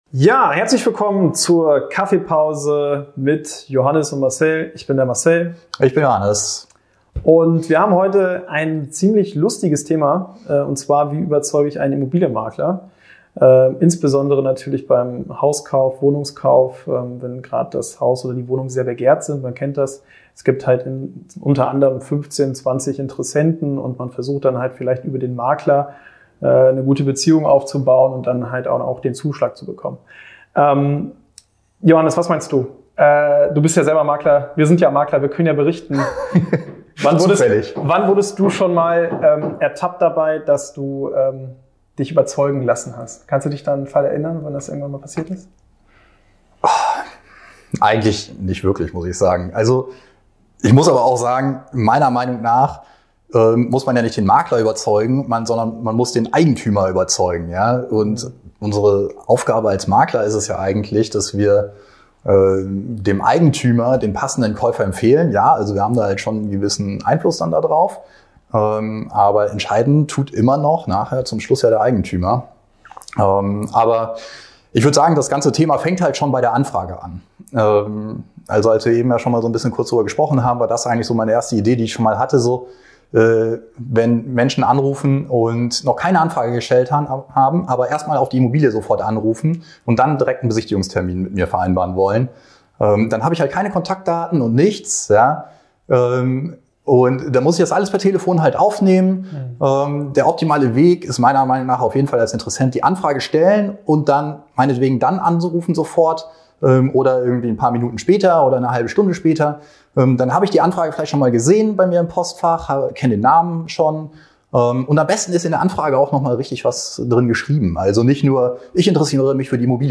Wir haben uns gedacht, wir berichten einfach mal ungeskriptet und unverfälscht direkt aus unserem Immobilienmakler-Alltag und quatschen einfach über unterschiedliche Themen aus der Immobilien-Branch...